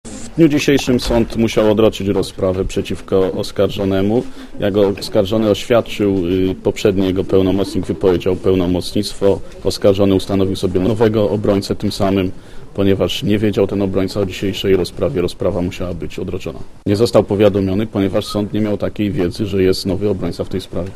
Komentarz audio